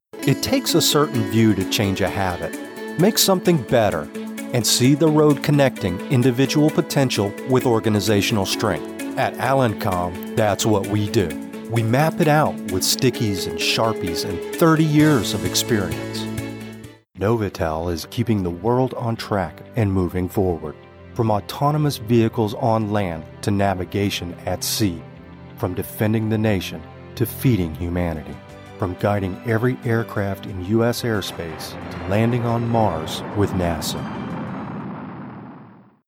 standard us
corporate narration
conversational
friendly
professional home studio
Corporate_demo_2.mp3